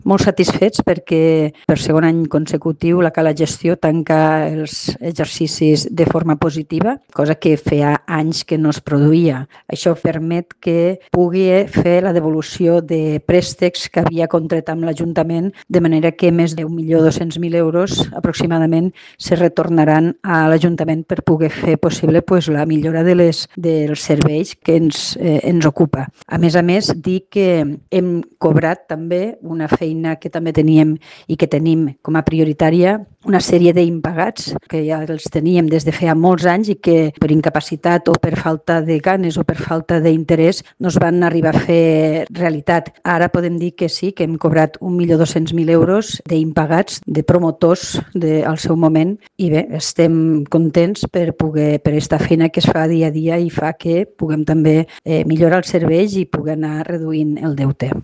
Eva del Amo, alcaldessa de l’Ametlla de Mar